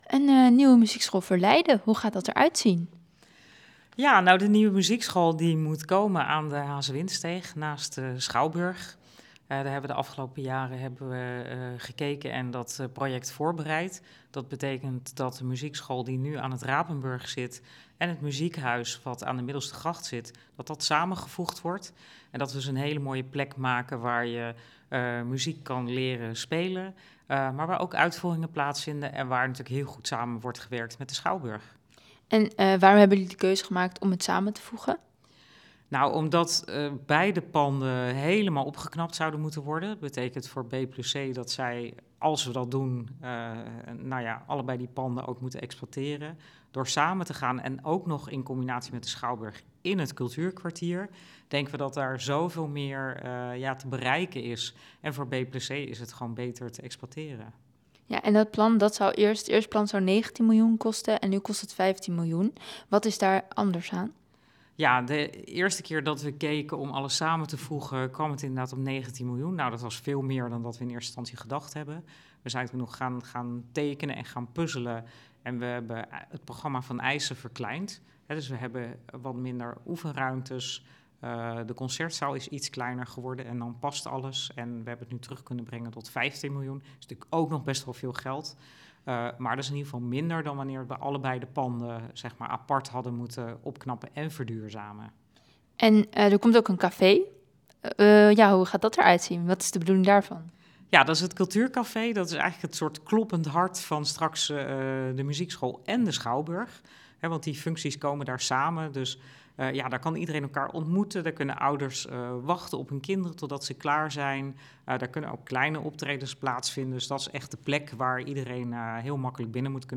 AUDIO: Wethouder Yvonne van Delft over de muziekschool.